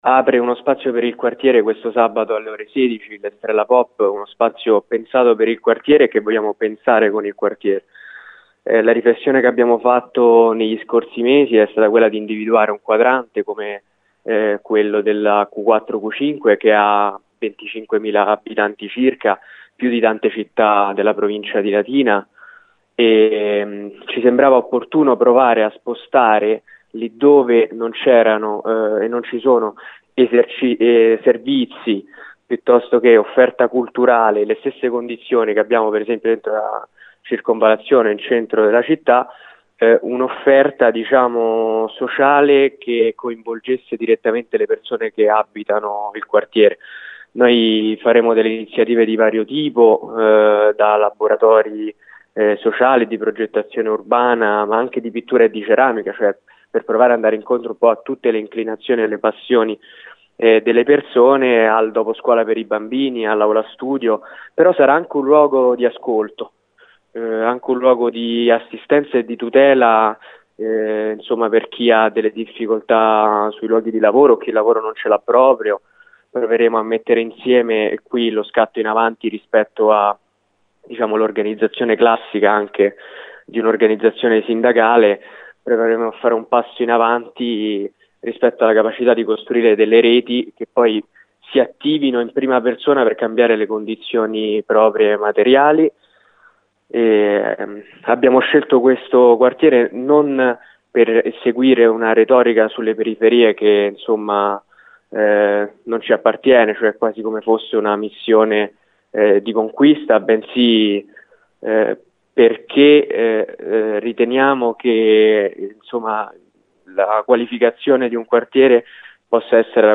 Ne abbiamo parlato per Gr Latina